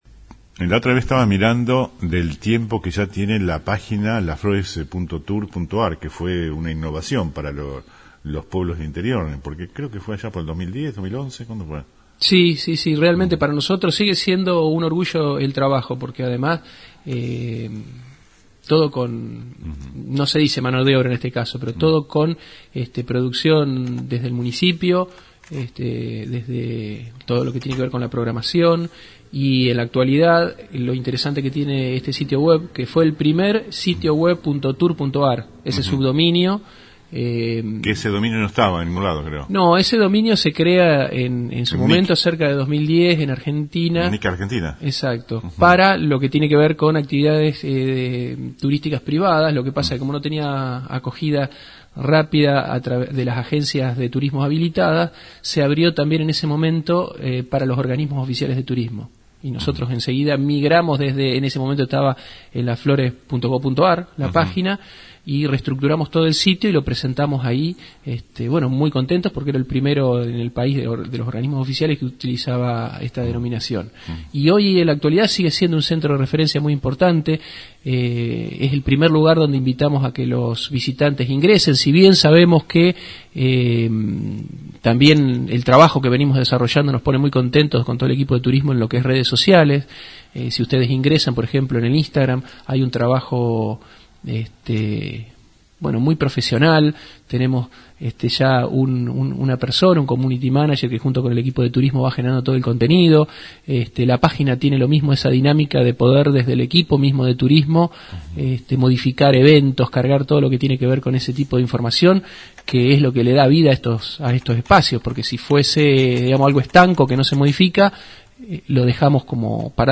Fue en la mañana de ayer en “El Periodístico” donde el licenciado ocupa el cargo en tres secretarías. En una extensa entrevista, el Chiodini puso en relieve diversos hechos y también reconoció diversos proyectos atrasados como la ampliación planta depuradora y el traslado de la de residuos urbanos, afirmando que la finalización de las obras penden de la coparticipación provincial, como los relegados de la nación.